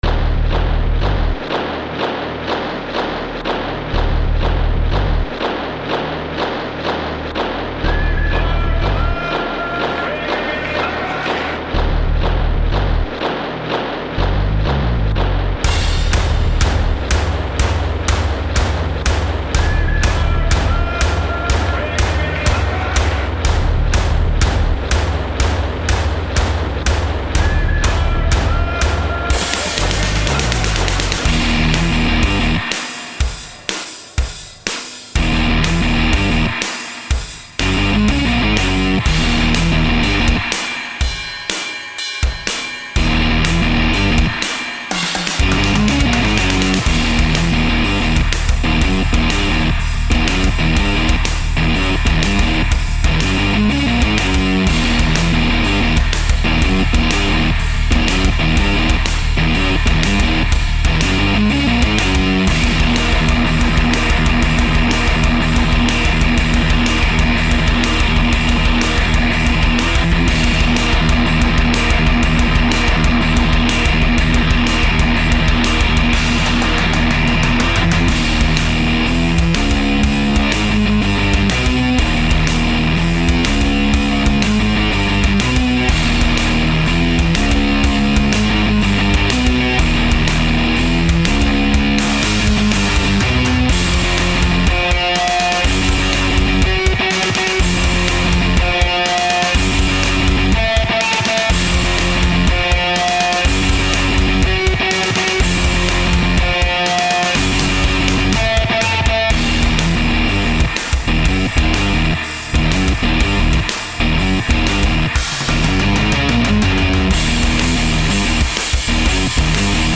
Kick up the bass, and less thick drums...
Needs more DnB especially at the end.